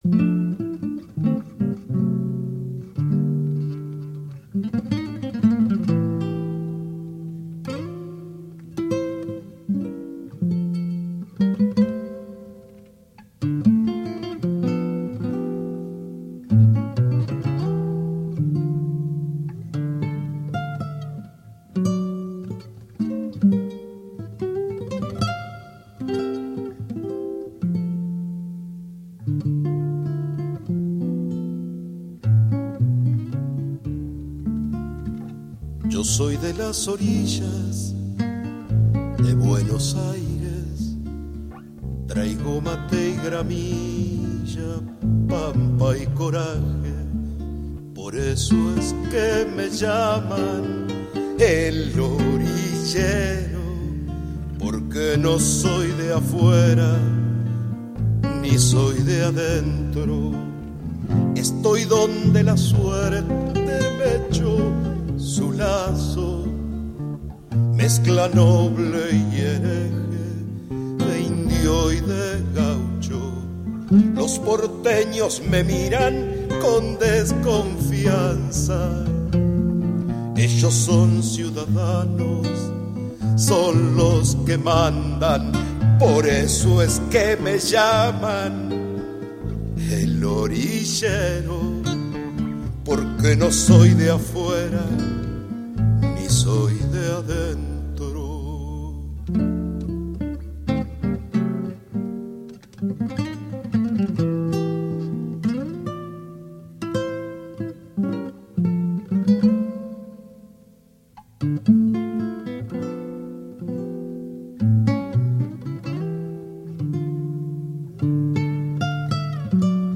Huella